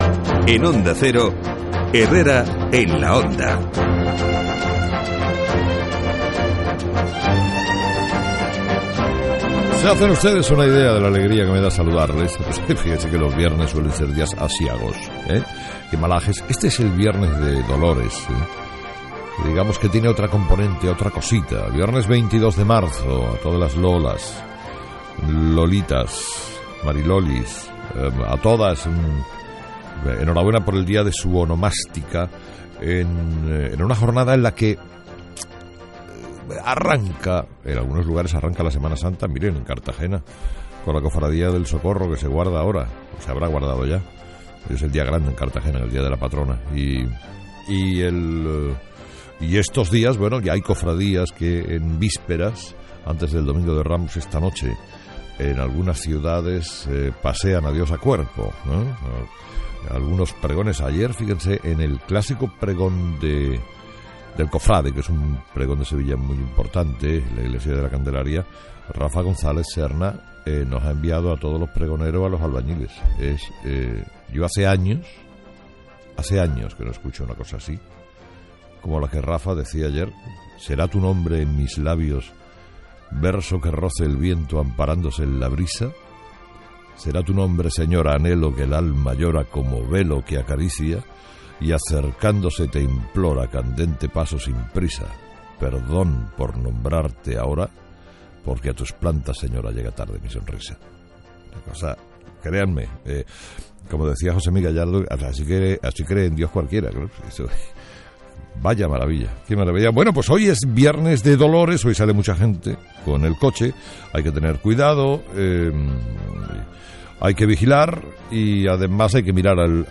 22/03/2013 Editorial de Carlos Herrera: 'Los EREs falsos es la corrupción con más volumen de la historia de Esp